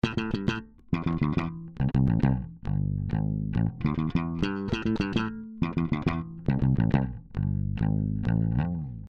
Většina je Fender CS JB 64, ve slapu je to MM Sabre.
Bass unprocessed.